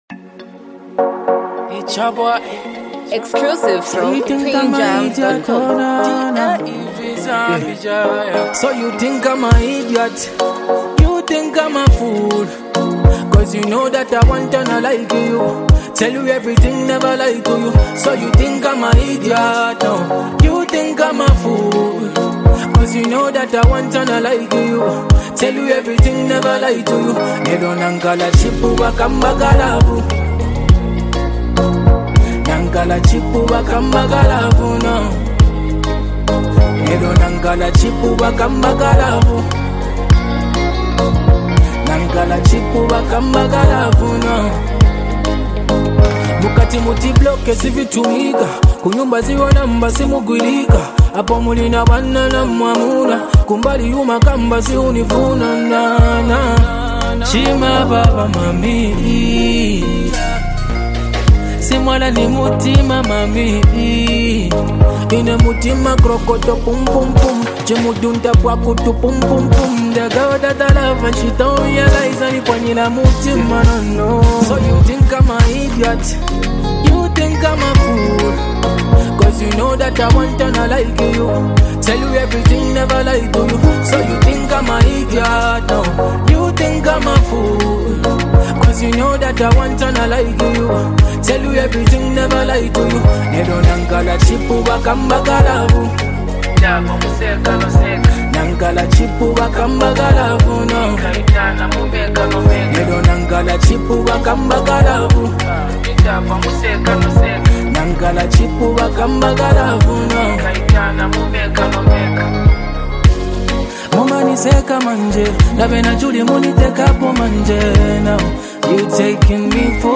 heartfelt and emotionally rich love song